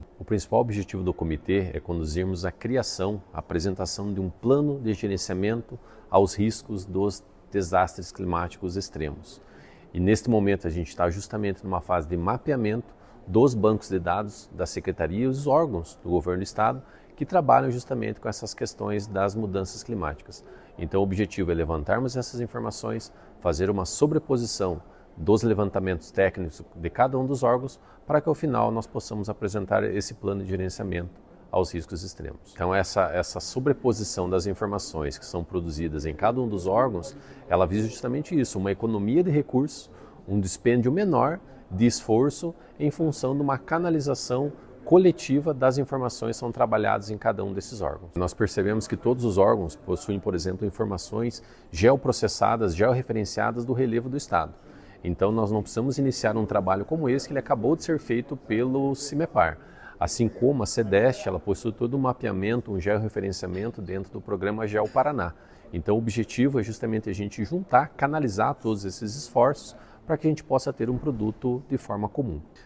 Sonora do coordenador da Defesa Civil, Ivan Fernandes, sobre a primeira reunião do Comitê Permanente de Governança Climática